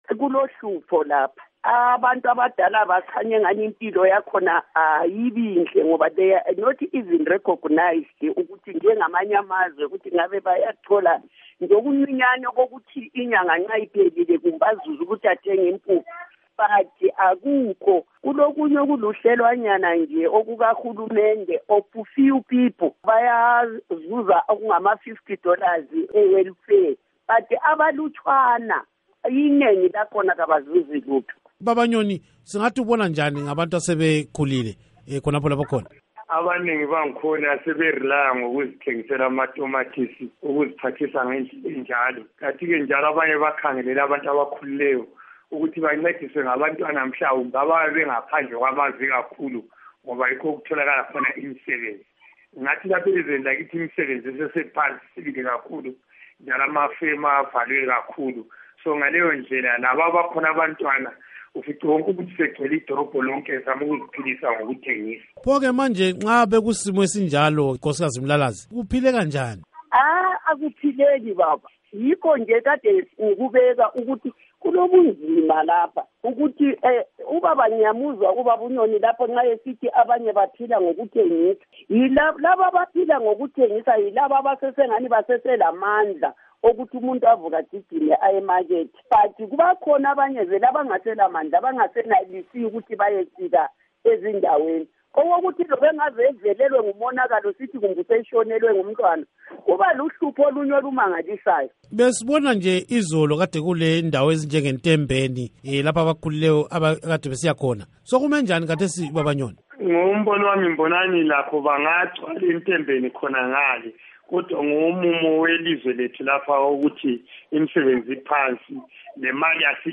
ukhansila welokitshi leMagwegwe
Ingxoxo